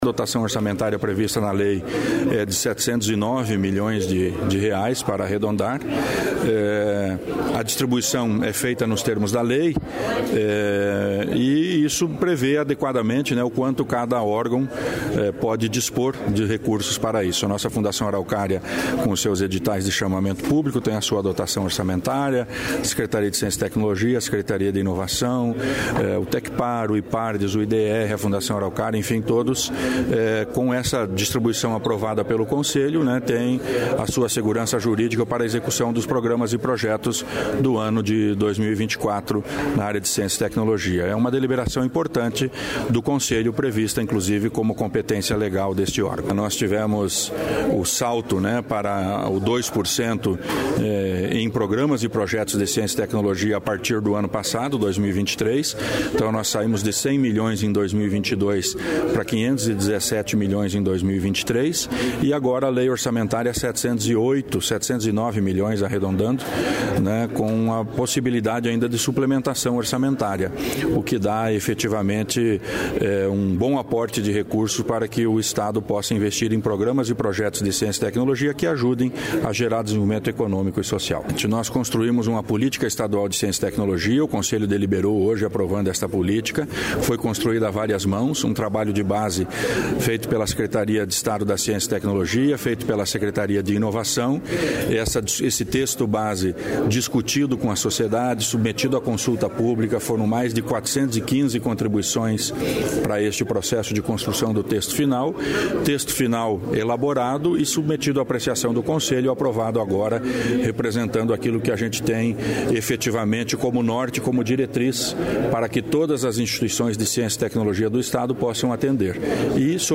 Sonora do secretário de Ciência, Tecnologia e Ensino Superior, Aldo Bona, sobre orçamento recorde para a ciência e tecnologia em 2024